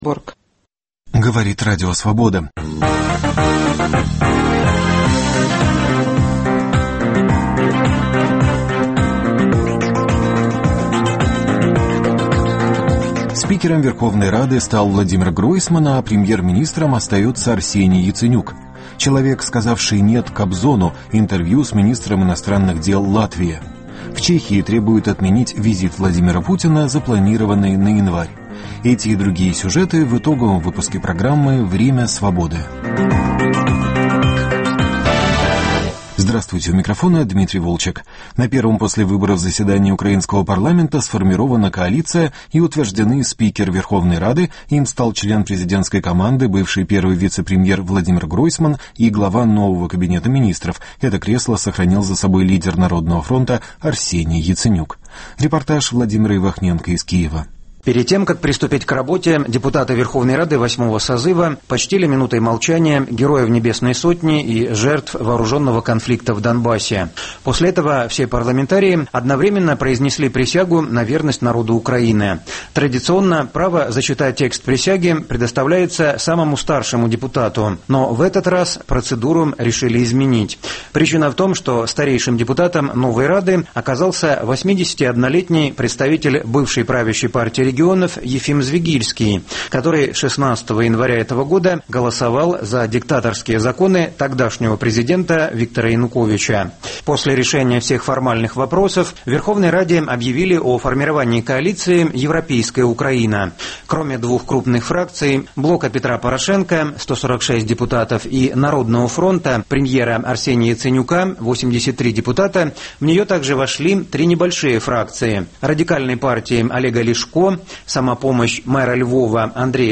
Спикером Верховной рады стал Владимир Гройсман, а премьер-министром остался Арсений Яценюк. Человек, сказавший «нет» Кобзону: интервью с министром иностранных дел Латвии. В Чехии требуют отменить визит Владимира Путина, запланированный на январь.